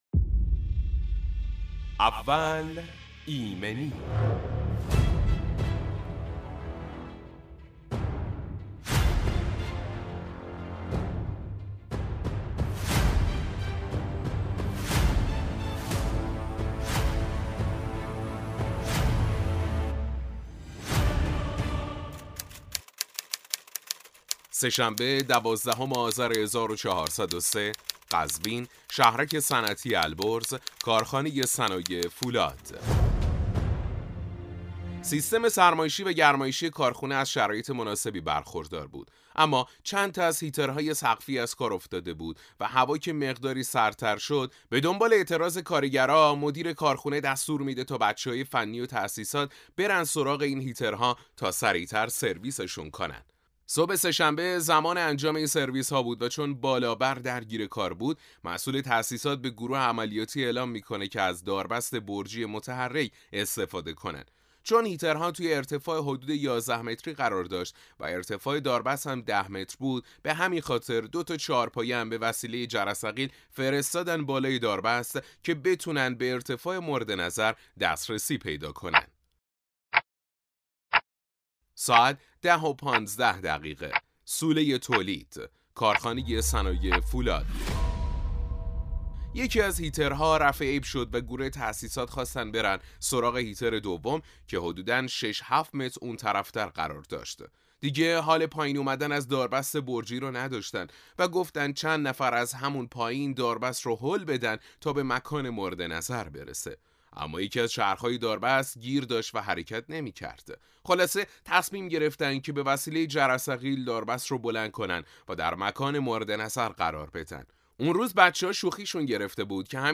گوش کنید به پادکست ایمنی ۱۲ — بررسی و تحلیل حادثه هنگام تعمیر هیترهای گرمایشی سقفی با روایت واقعی و نکات کلیدی ایمنی از متخصص HSE